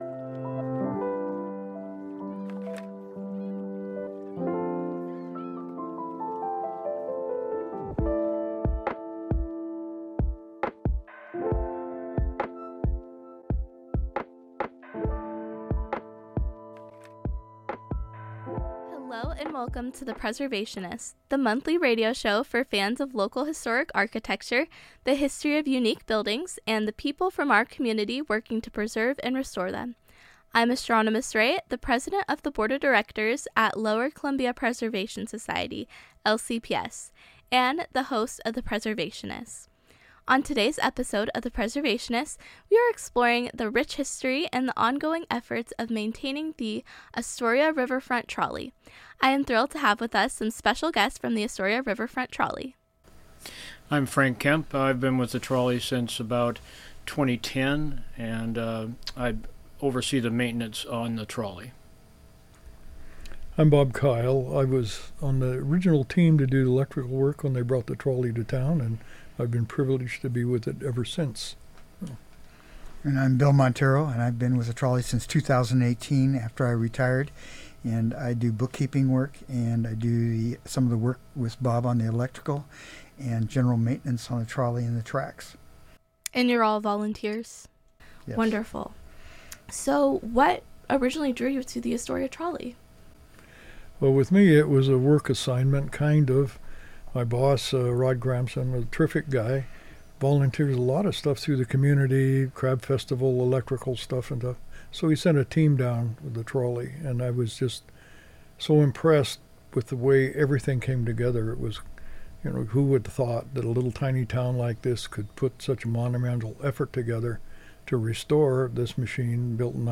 Listen in as they share insights on how the Trolley is maintained.